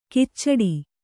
♪ kiccaḍi